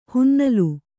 ♪ hunnalu